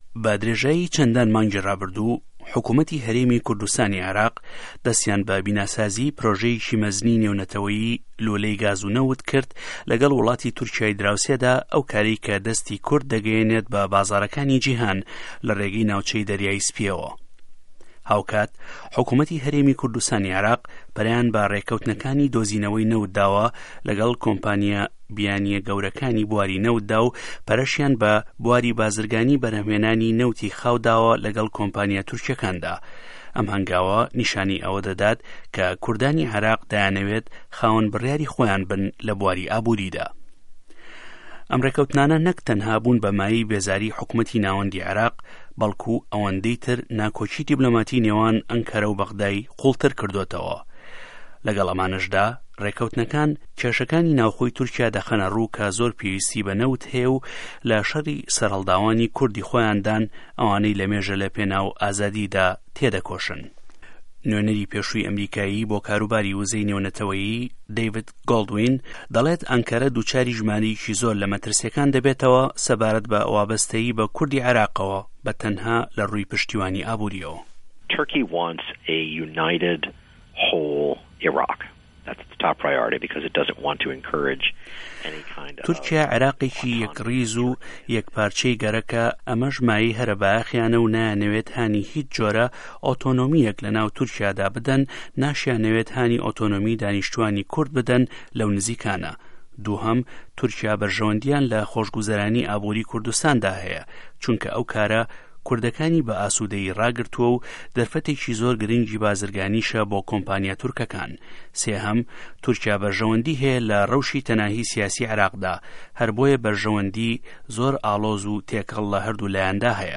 ڕاپـۆرتی هه‌رێمی کوردستان و نه‌وت